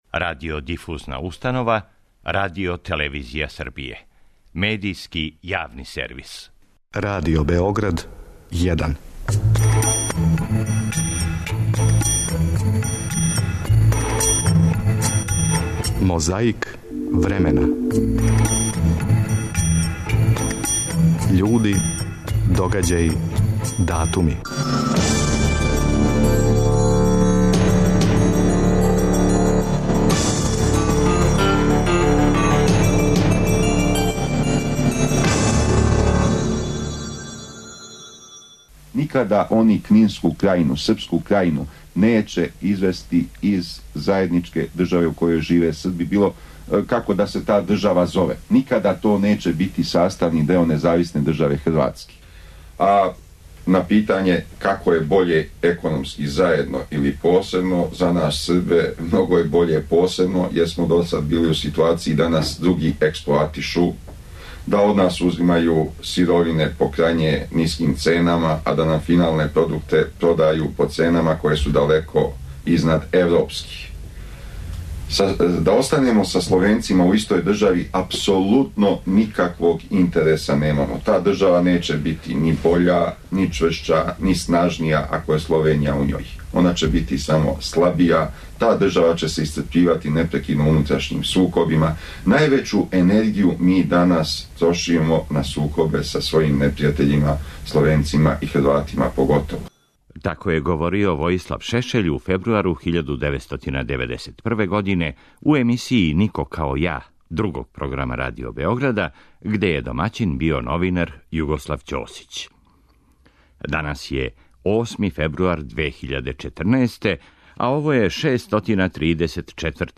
Звучна коцкица нас враћа у фебруар 1991. године када је Војислав Шешељ говорио у емисији 'Нико као ја'.
Истог дана, али 1990. године, тадашњи председник председништва СФРЈ, Јанез Дрновшек имао је уводно излагање на заседању оба већа савезне Скупштине.